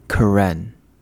Karen_(ethnolinguistic_group)_pronunciation.mp3